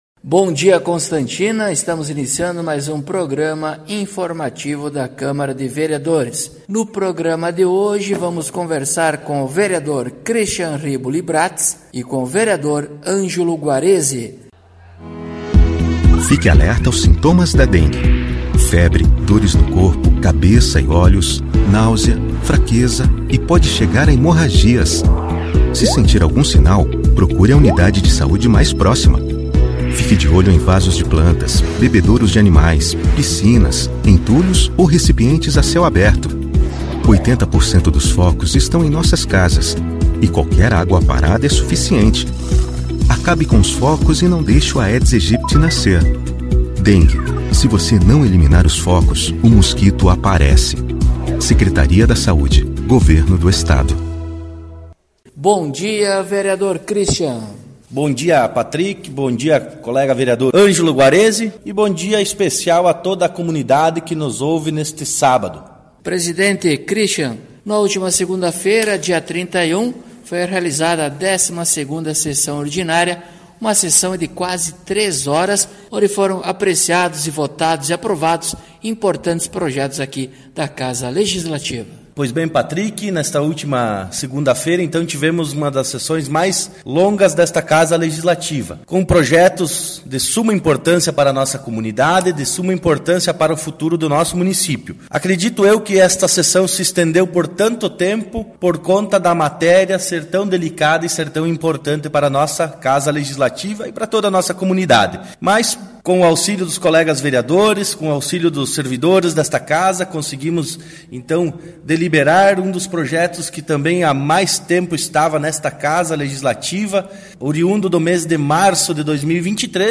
Acompanhe o programa informativo da câmara de vereadores de Constantina com o Vereador Cristian Riboli Bratz e o Vereador Angelo Guarezi.